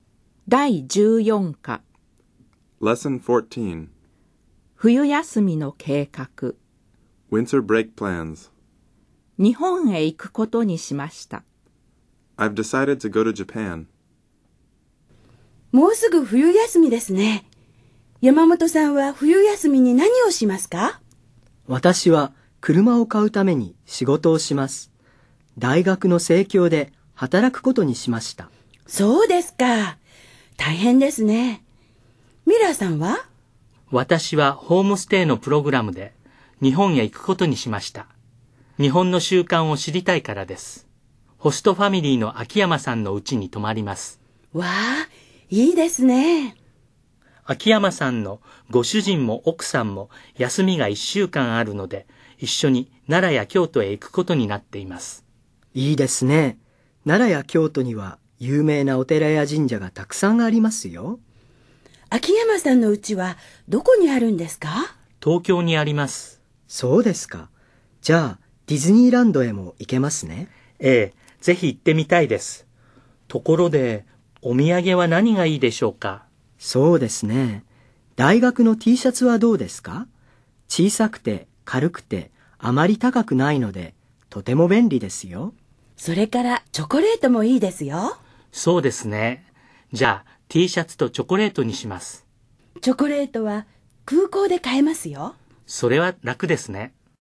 dialog14.mp3